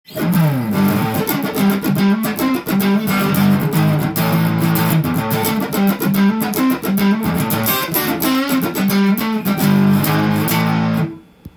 試しに弾いてみました
フロントで弾くとカッティングに合いそうな優しい音で
なおかつシングルコイルの細いサウンドも健在でした。